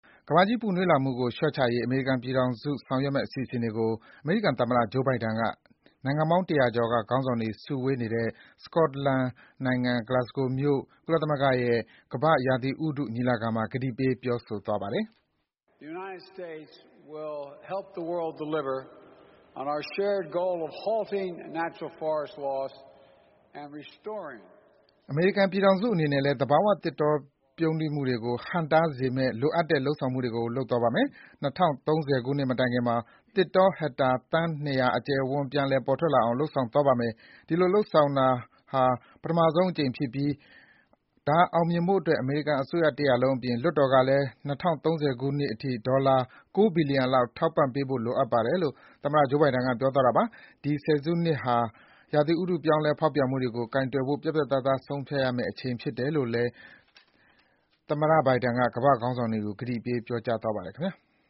ကမ္ဘာ့ရာသီဥတုညီလာခံမှာ ကန်သမ္မတမိန့်ခွန်းပြော